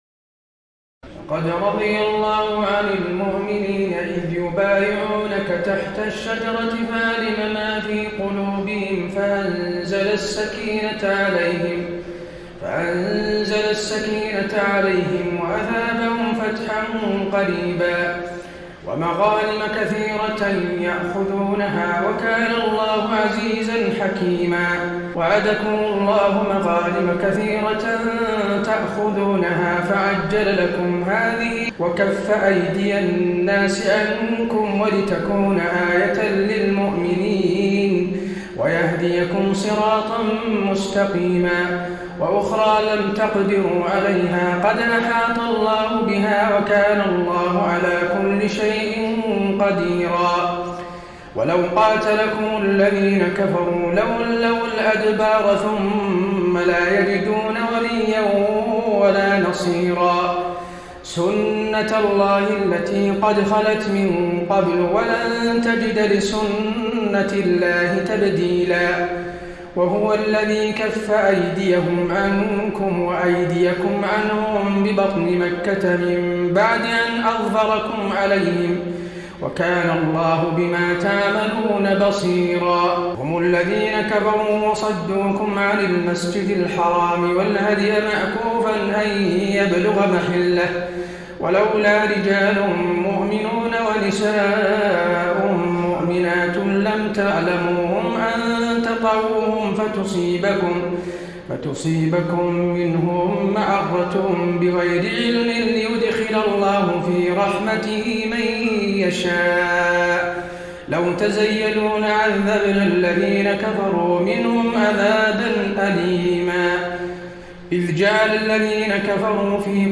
تراويح ليلة 25 رمضان 1433هـ من سور الفتح (18-29) الحجرات و ق و الذاريات (1-23) Taraweeh 25 st night Ramadan 1433H from Surah Al-Fath and Al-Hujuraat and Qaaf and Adh-Dhaariyat > تراويح الحرم النبوي عام 1433 🕌 > التراويح - تلاوات الحرمين